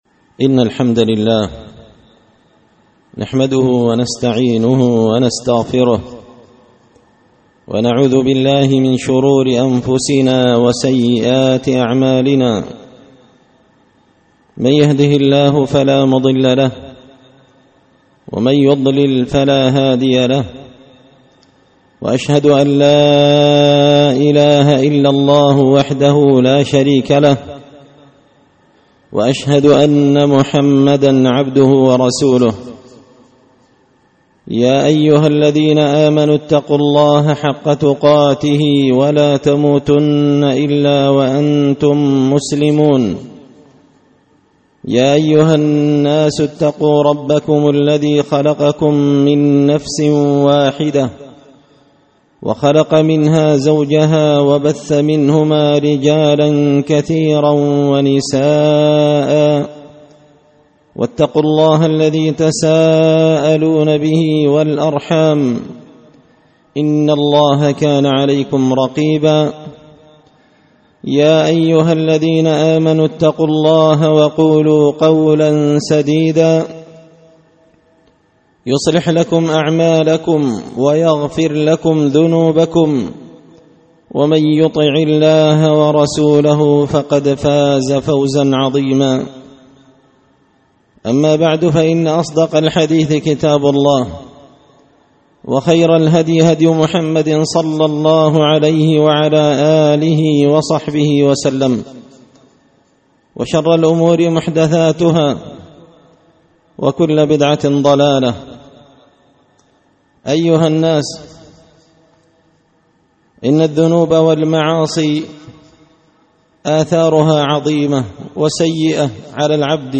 خطبة جمعة بعنوان – لاتبغ ولا تكن باغيا
دار الحديث بمسجد الفرقان ـ قشن ـ المهرة ـ اليمن